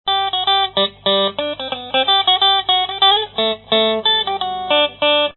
安第斯笛
Tag: 天然的 天然的美国人 旋律 风仪 ASPMA 美国人 本地美国人 长笛 长笛 处理 凯纳